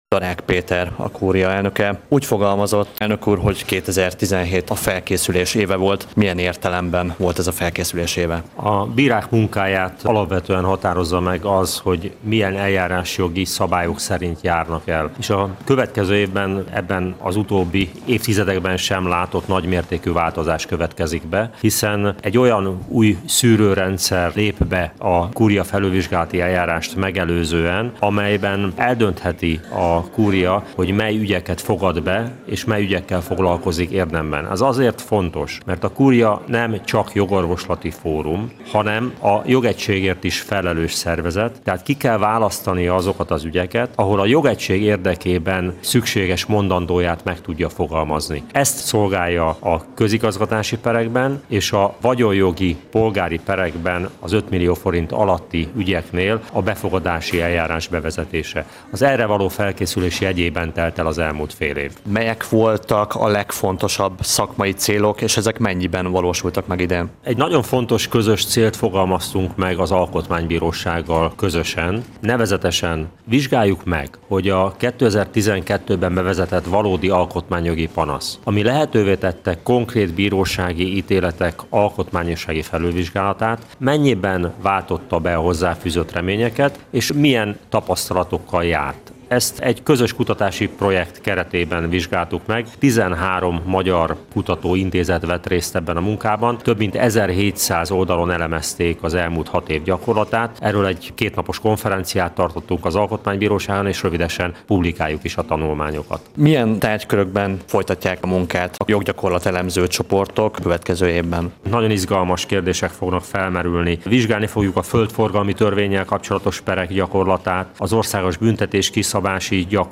A Kúria félévértékelő sajtótájékoztatójáról számolt be a Kossuth Rádió 180perc című műsora 2017. december 29-én.